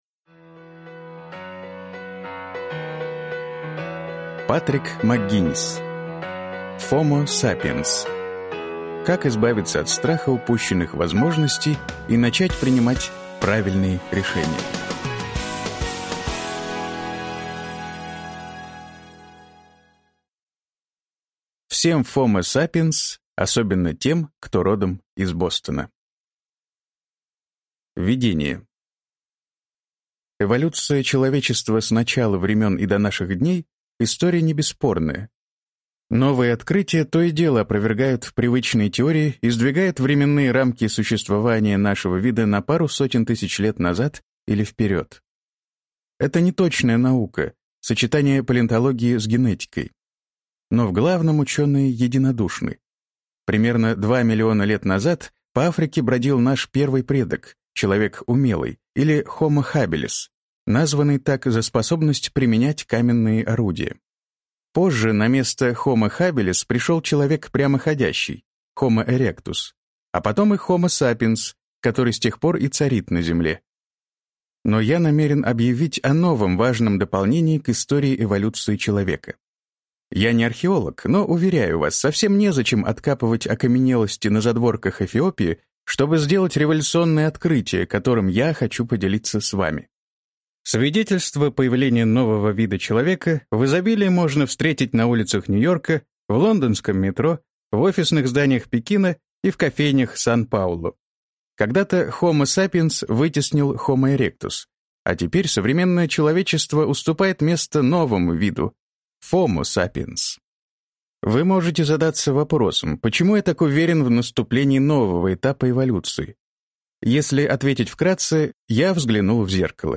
Аудиокнига FOMO sapiens. Как избавиться от страха упущенных возможностей и начать принимать правильные решения | Библиотека аудиокниг